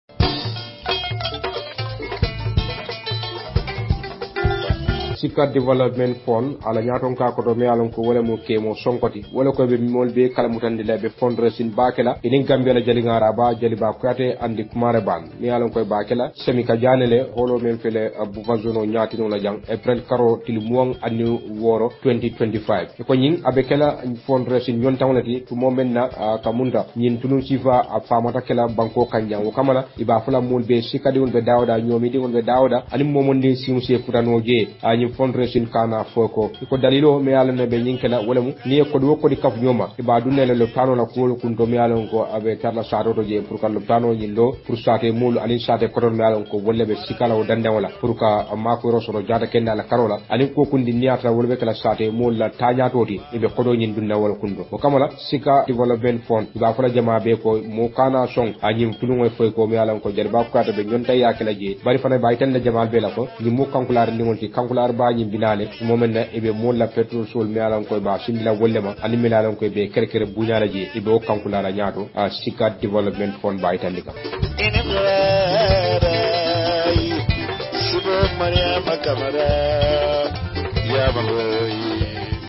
A Message from Jaliba Kuyateh
sika_ad_audio.mp3